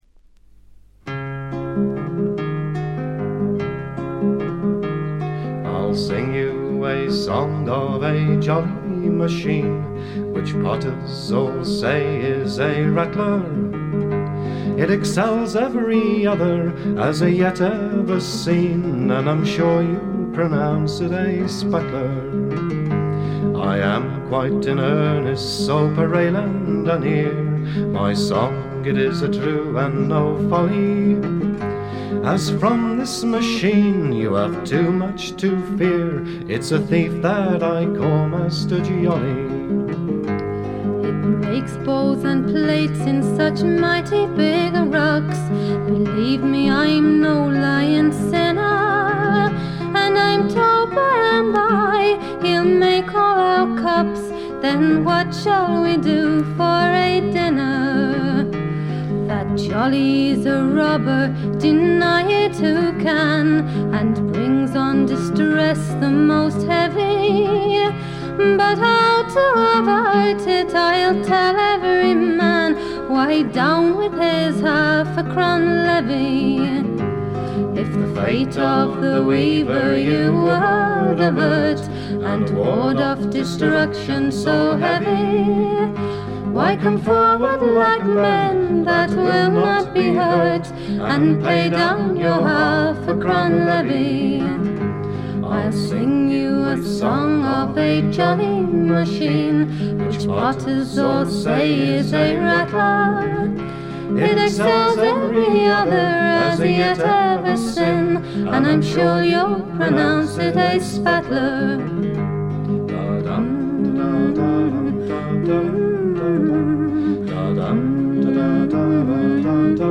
レア度、内容ともに絶品のフィメールトラッドフォークです。
試聴曲は現品からの取り込み音源です。